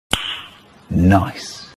Meme sound